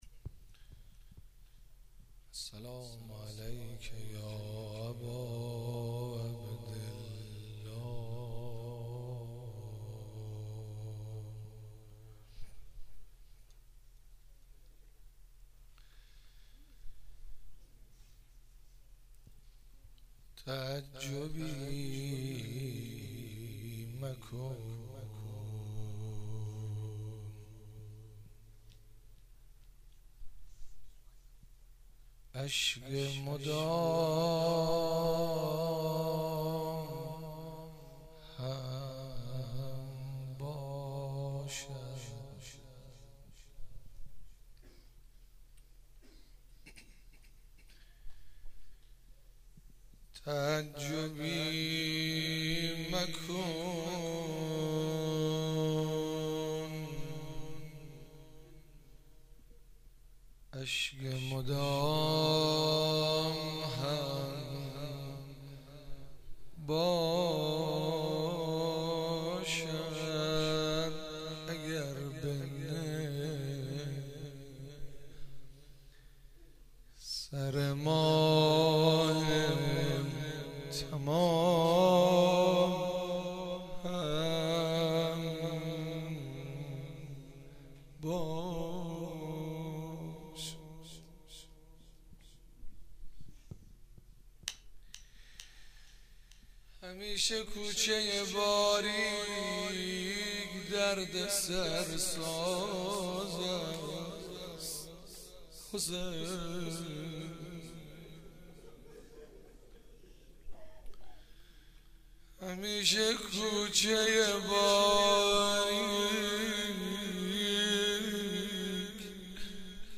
مراسم شب 15 محرم الحرام 96
حسینیه حضرت زینب (سلام الله علیها)
روضه
شور و نوا و روضه